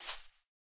Minecraft Version Minecraft Version 25w18a Latest Release | Latest Snapshot 25w18a / assets / minecraft / sounds / block / azalea / step6.ogg Compare With Compare With Latest Release | Latest Snapshot
step6.ogg